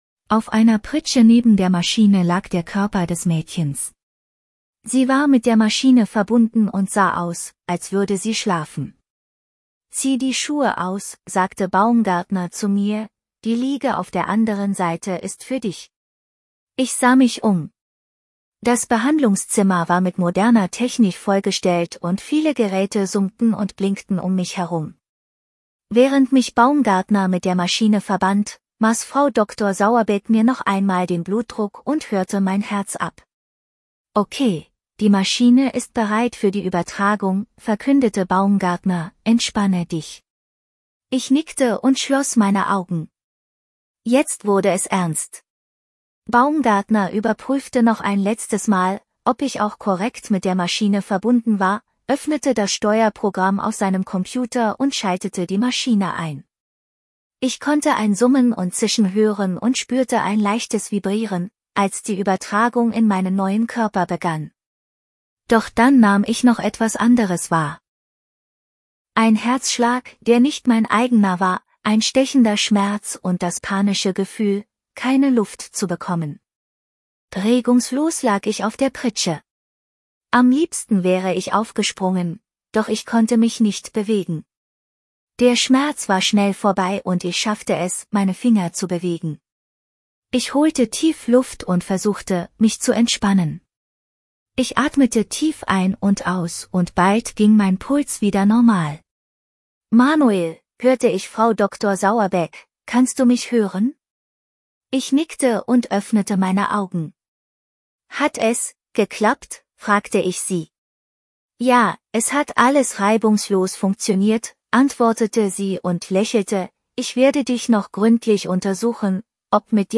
Text to Speech
Diesen Text habe ich nun von verschiedenen KI-Werkzeugen lesen lassen.
Ich habe bei Luvvoive Deutsch eingestellt und es mit den Stimmen Katja und Amala versucht.